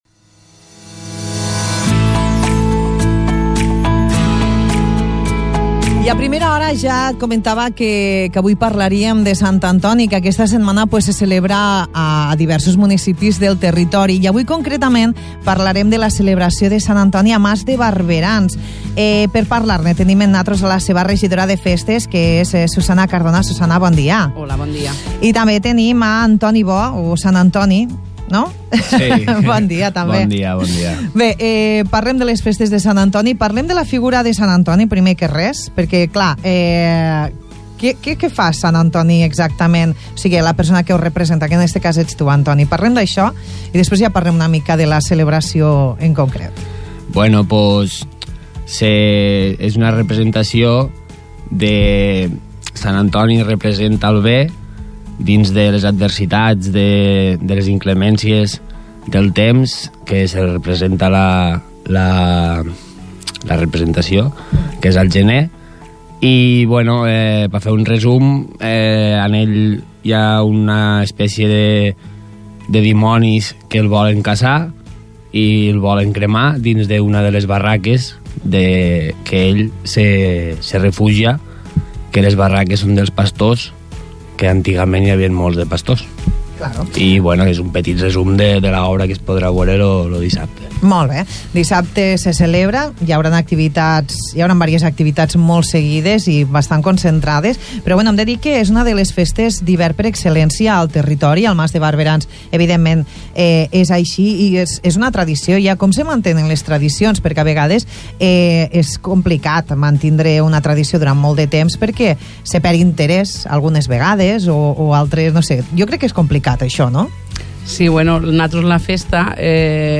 Avui han visitat els estudis d’Imagina Ràdio Susana Cardona, regidora de Festes